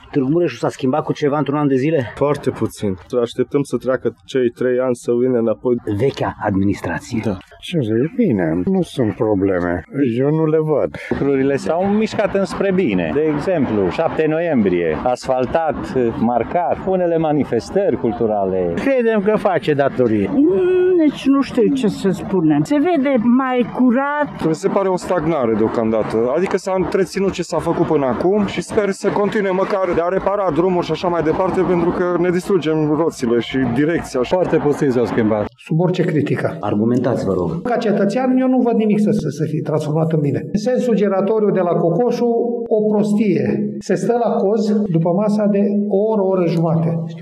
Părerile târgumureșenilor sunt împărțite. Unii spun că s-a făcut puțin în ultima perioadă, alții sunt mulțumiți și există și nostalgici după vechea administrație a municipiului: